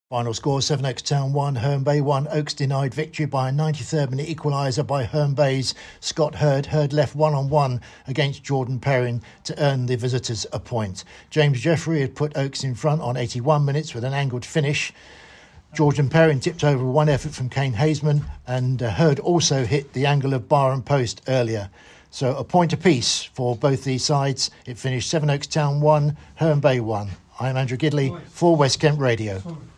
Report